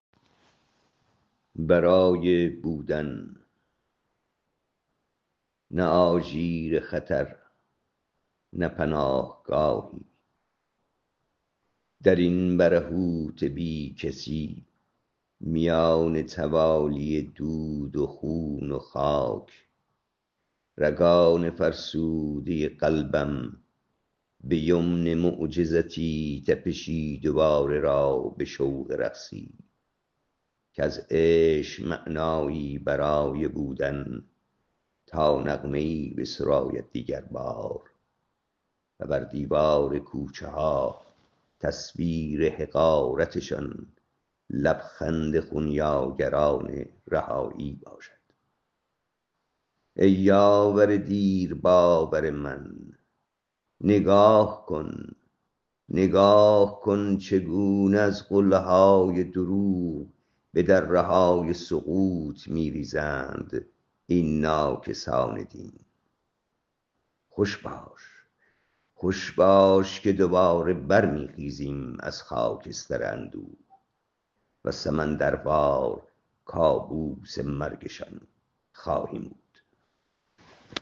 این شعر را با صدا شاعر بشنوید